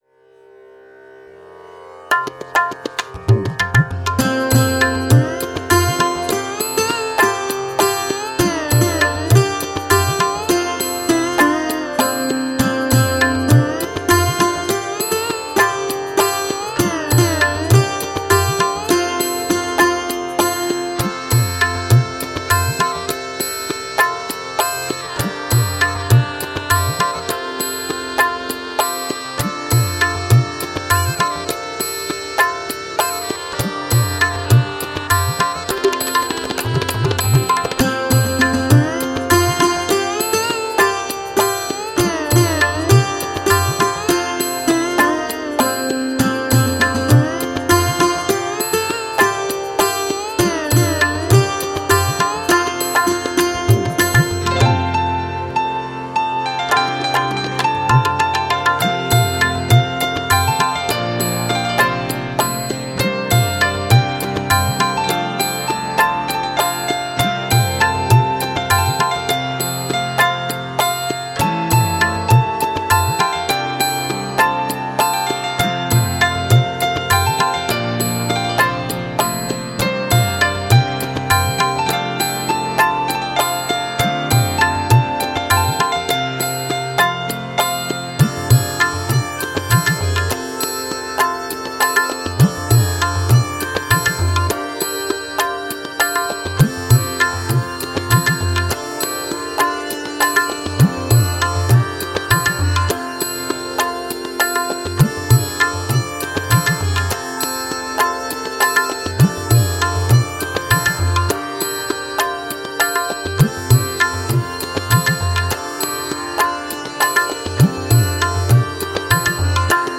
🎵 Instrumental
10-Instrumental-1.mp3